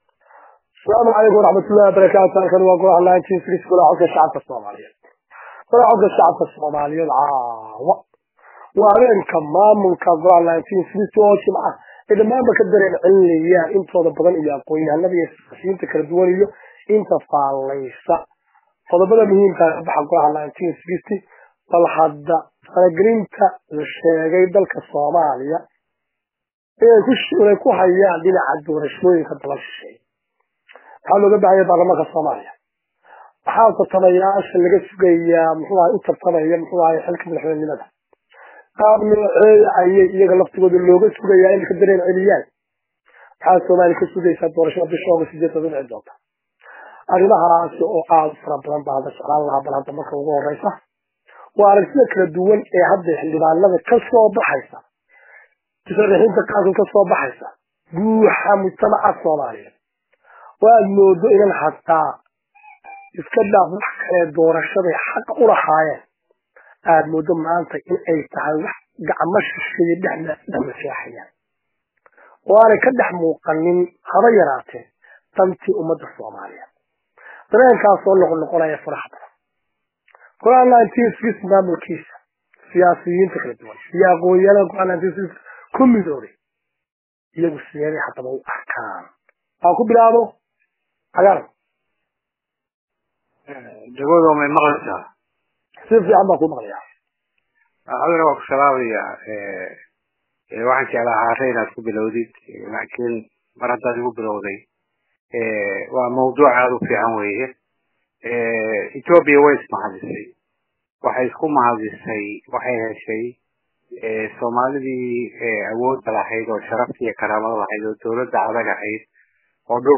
4 feb 2017 (Puntlandes) Waraysigan oo aad u xiisa badan ayna ka qayb qaateen odayaasha iyo maamulayaasha golaha1960,golaha codka shacabka somaliyeed,ayey kaga hadleen faragelinta ay wadamada shisheeye ku hayaan dalka somaliya iyo saamaynta ay ku leeyihiin doorashada madaxweyne ee la filayo iney ka dhacdo dalka somaliya 2017ka, Sidoo kale waxay BAAQ u direen Baarlamaanka somaliya ee labada aqal ,Taasi oo sheegeen in ummadda somaliyeed ka sugayaan iney u dooraan Madaxweyne wanaagasan, somaliyana ka saari kara xaaladda ay maanta ku sugan tahay.